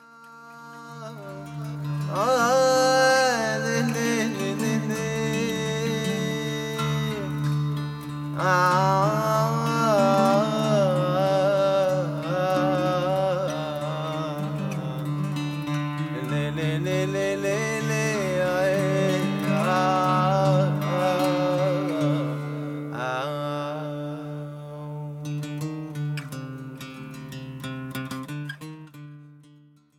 contemporary versions of 28 classics of Jewish music